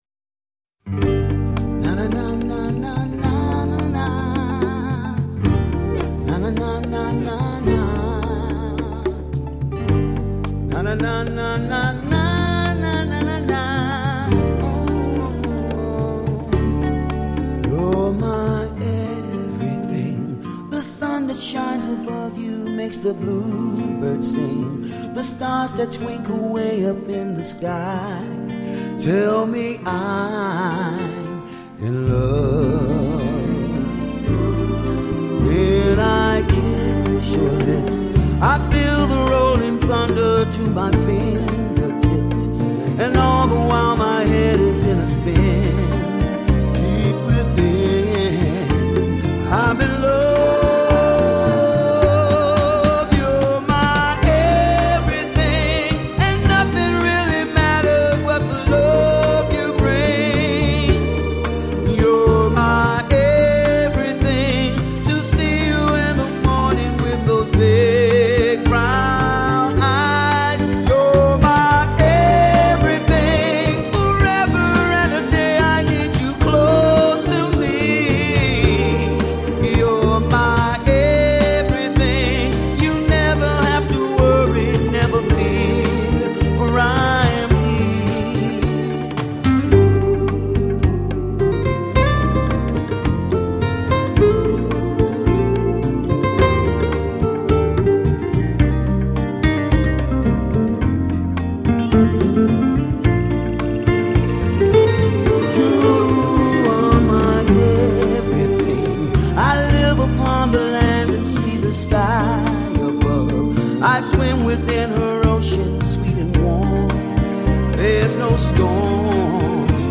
10 Rumba